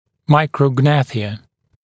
[ˌmaɪkrə’gnæθɪə][ˌмайкрэ’гнэсиэ]микрогнатия